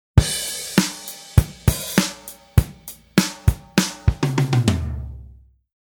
たとえば↓のようなドラムパターンの場合、
今回は↓のように表記していきます（ハイハットの○はオープンハイハット）。
drum-score-sample.mp3